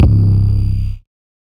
808_Oneshot_Static_C
808_Oneshot_Static_C.wav